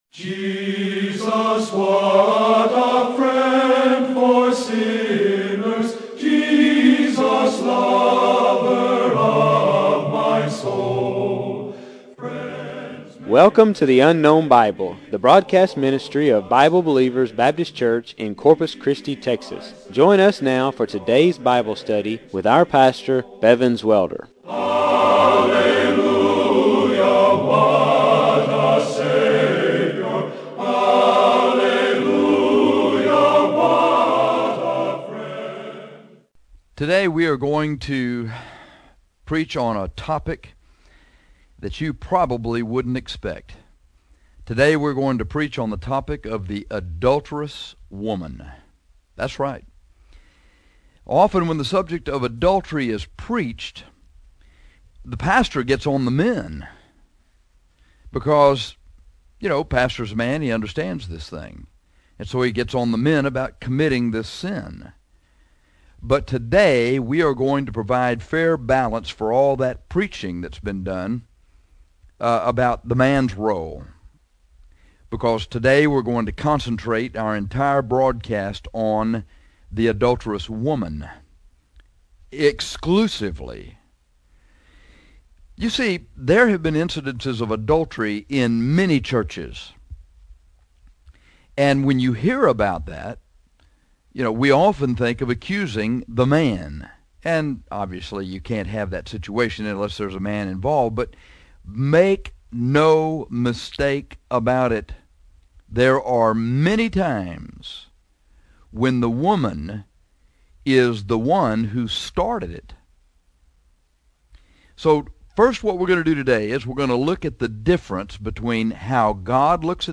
This lesson is about the adulterous woman, exclusively.